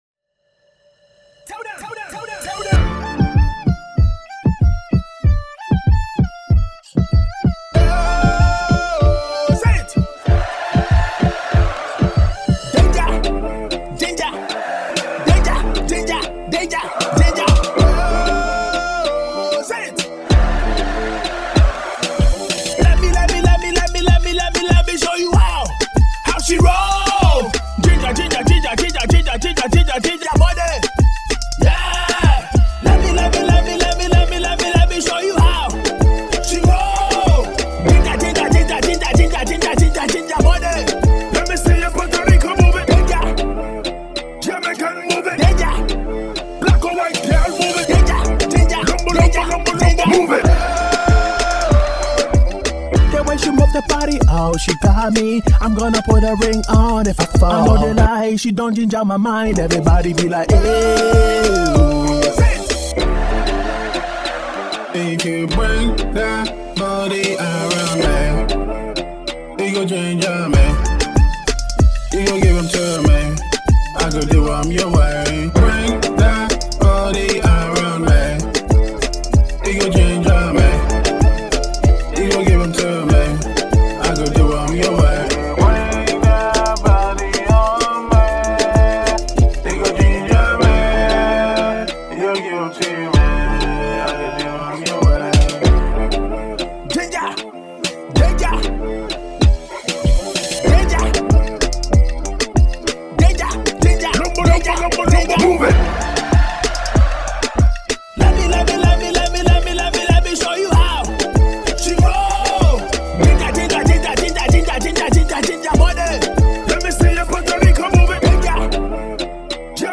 Afro-Swing and Afrobeats
Faster Version 1